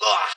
DDW ILL G8z Chant.wav